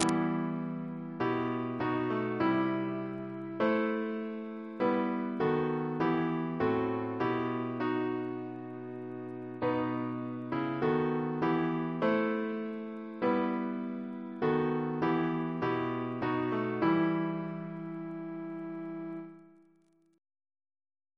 Double chant in E minor Composer: Christopher Teesdale (1782-1855) Reference psalters: H1982: S6; OCB: 39